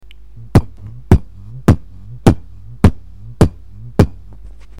Форум российского битбокс портала » Реорганизация форума - РЕСТАВРАЦИЯ » Выкладываем видео / аудио с битбоксом » Мои Тупые Биты.
Bass.mp3